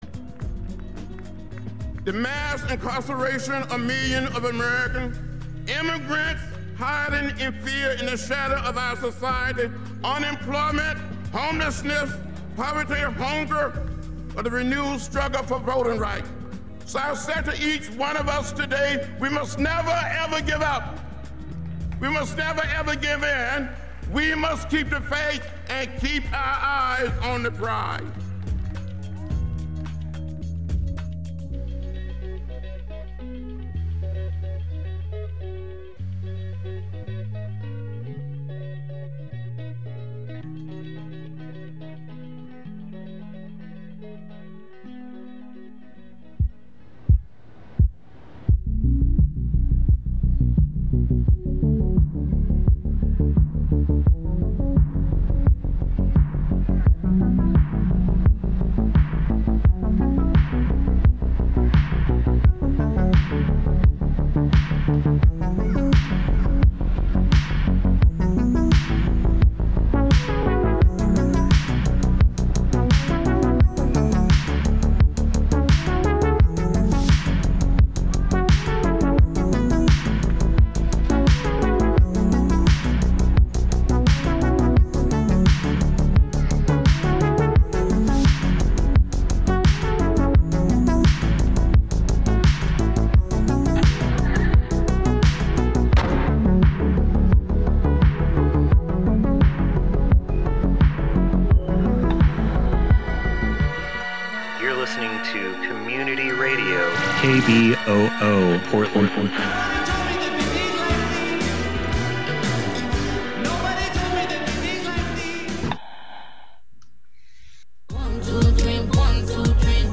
Please join us for Transpositive on KBOO Community Radio on Monday, March 8th for International Women's Day.